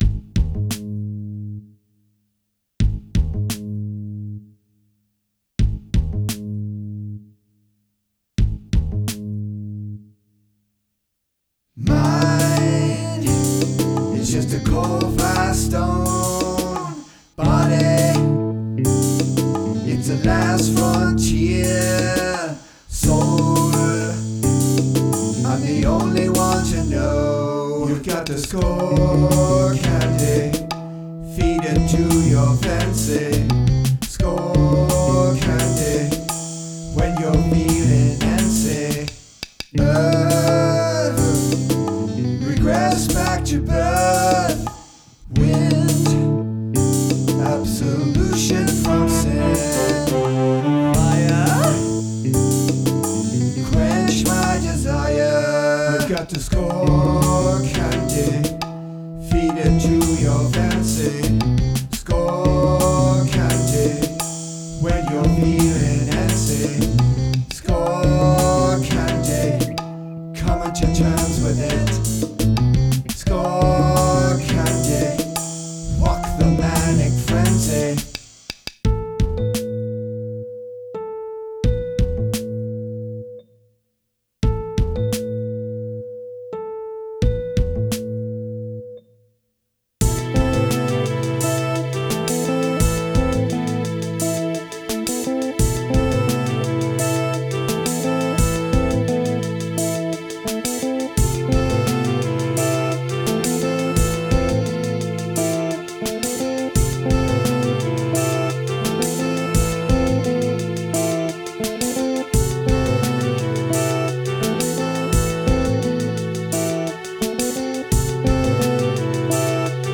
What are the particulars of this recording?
Music only; no visuals, no video